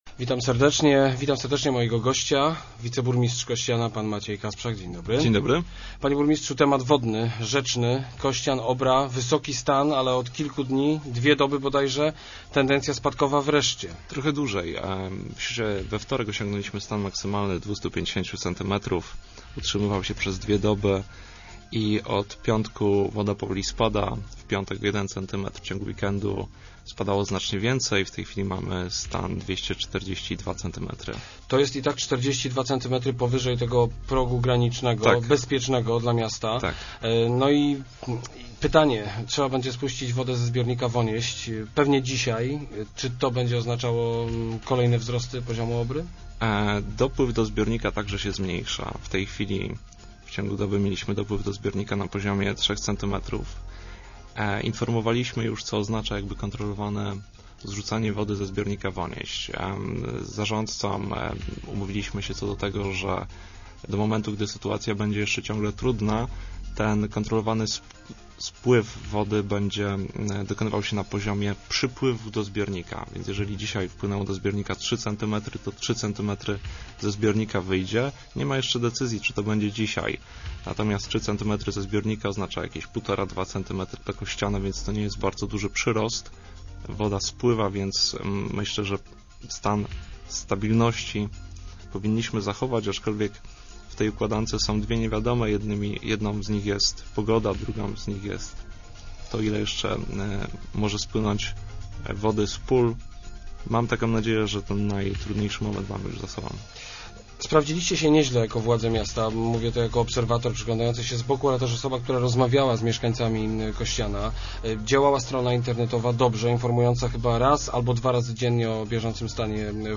kasprzak80.jpgNa regulację Obry w Kościanie potrzeba około ośmiu milionów złotych – mówił dzisiejszy gość Rozmów Elki, wiceburmistrz Maciej Kasprzak.  Samorząd Kościana ma zapewnienie o przekazaniu części potrzebnych pieniędzy z budżetu wojewody.